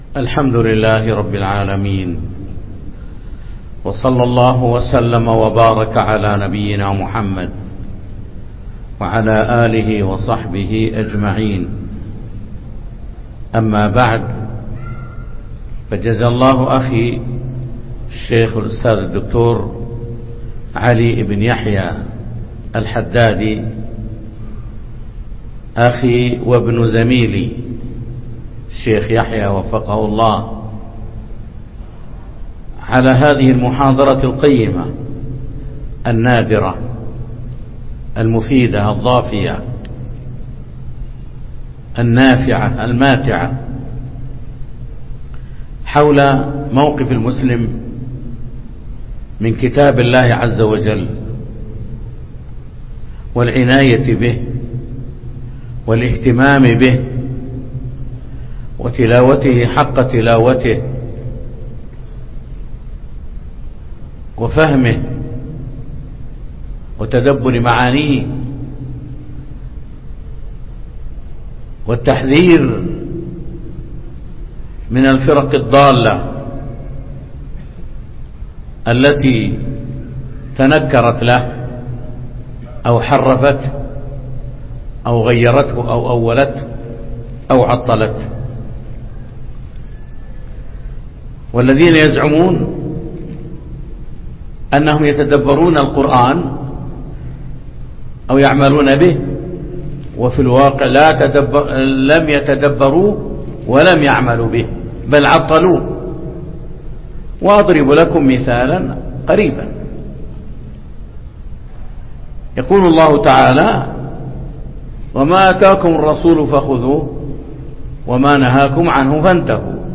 محاضرة
مسجد قباء